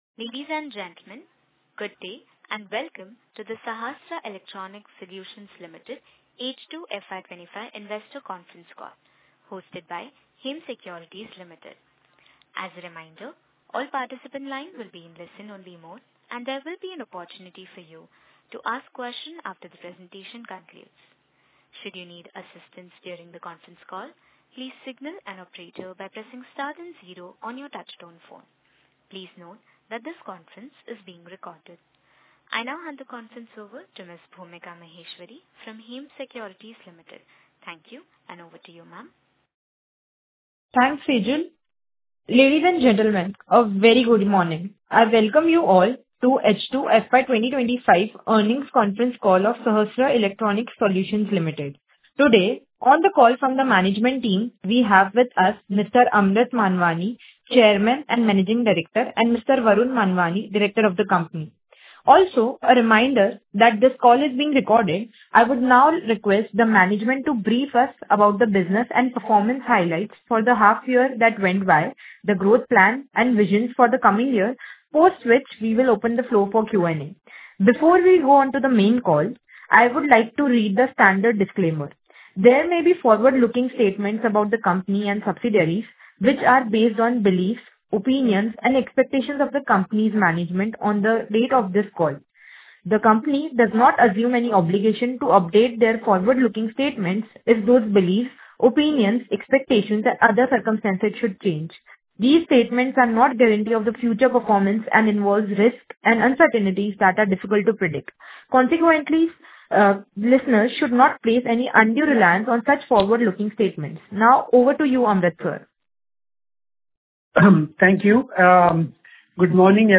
Conference Call Recording